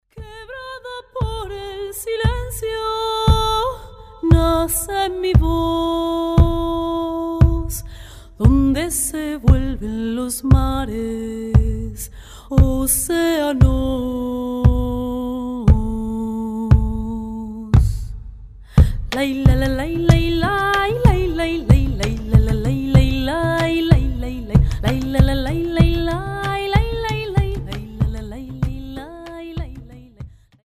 cello, voice, and percussion
transverse flute and percussion